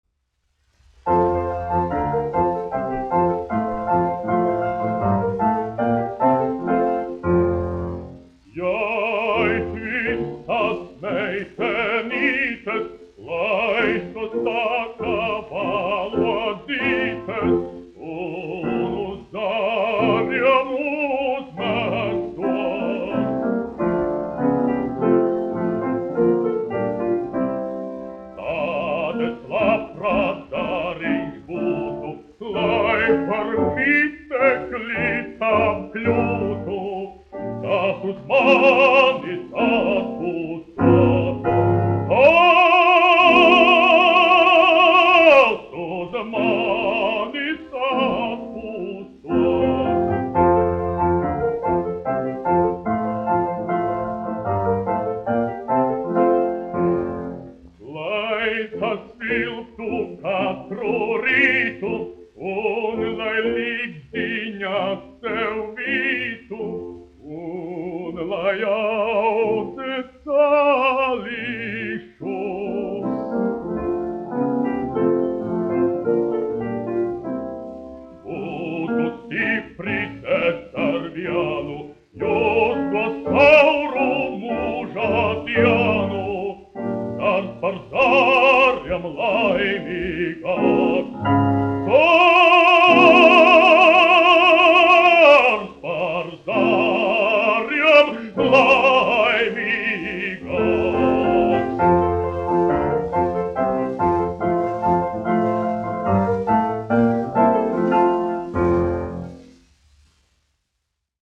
1 skpl. : analogs, 78 apgr/min, mono ; 25 cm
Operas--Fragmenti, aranžēti
Skaņuplate